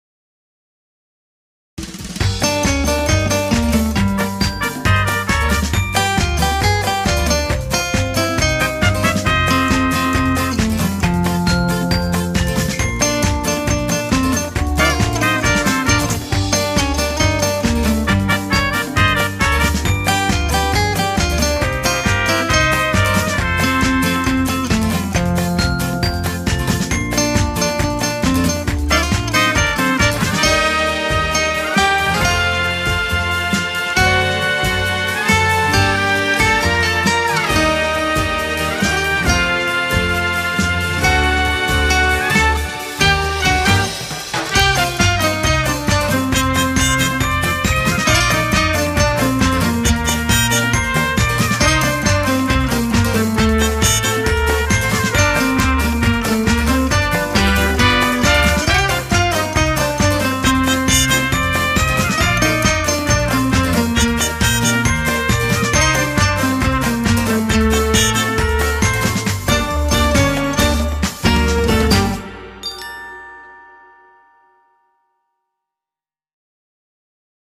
Enstrümantal
tema dizi müziği, mutlu neşeli eğlenceli fon müziği.